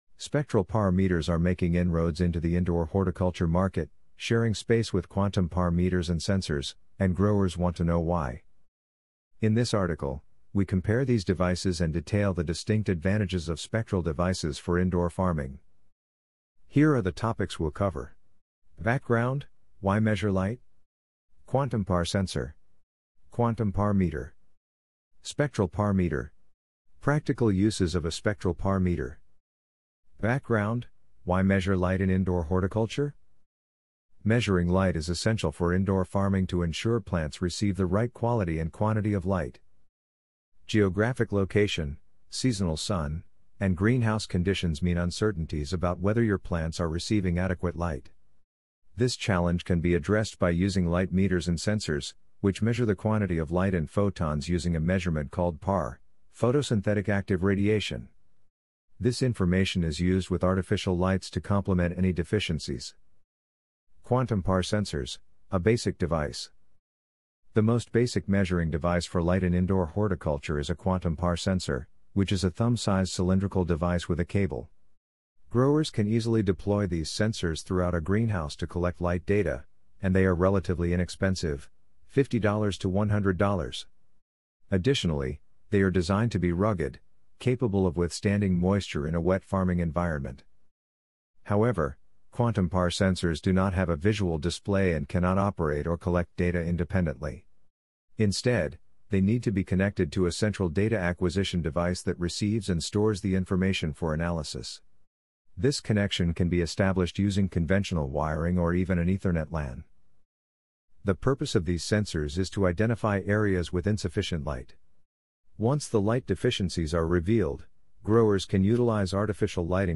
What-is-a-Spectral-PAR-Meter-en-narration-v2.mp3